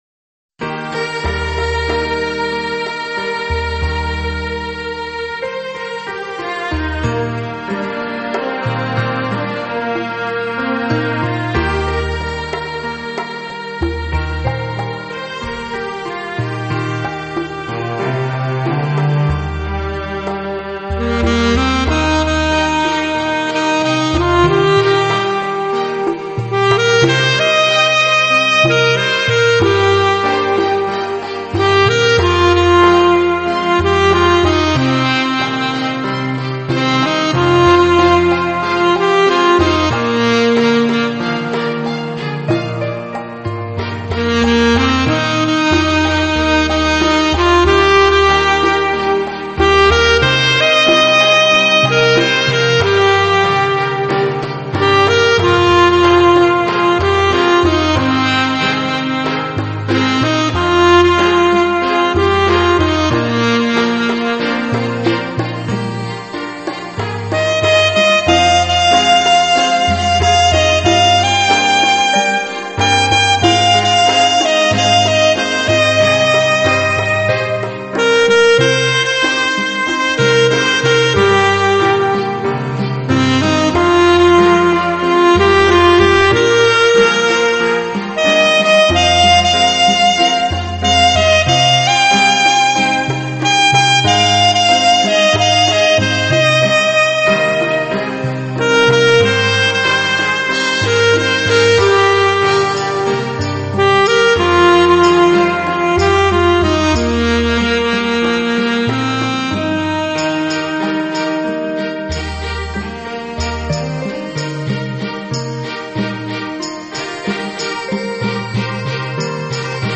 荡气回肠、千回百转，萨克斯风温情释放！